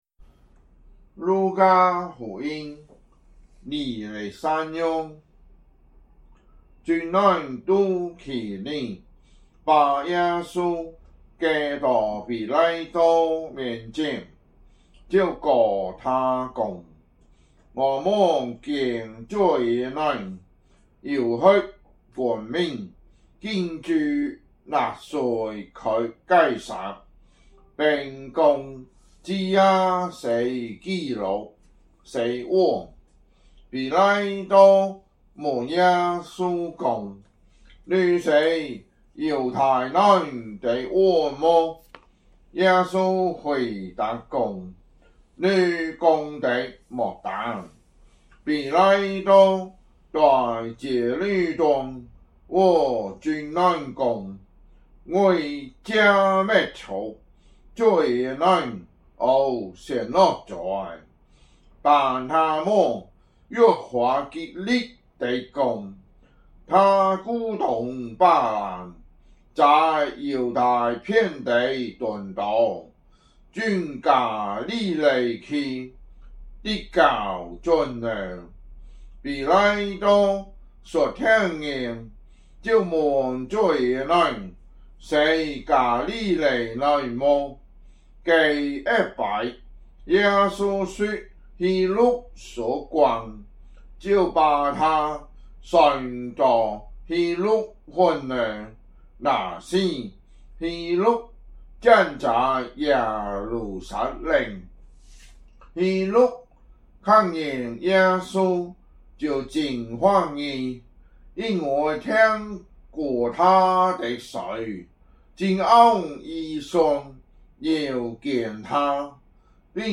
福州話有聲聖經 路加福音 23章